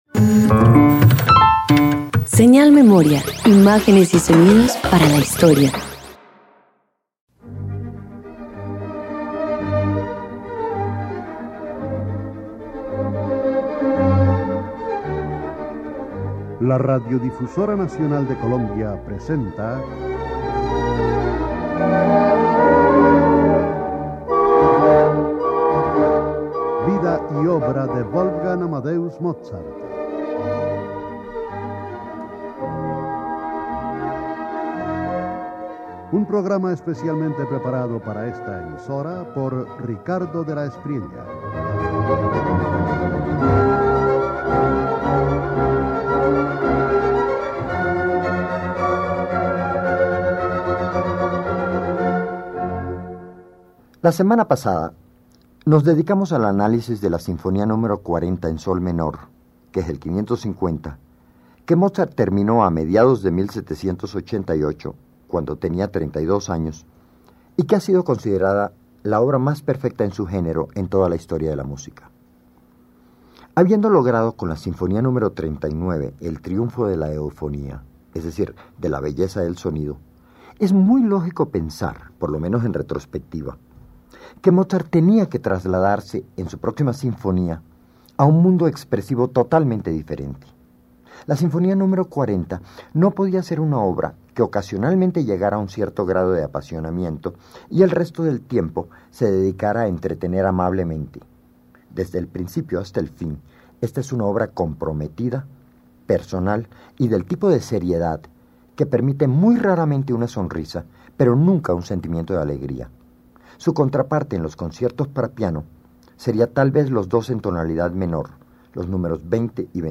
Radio colombiana
en Sol menor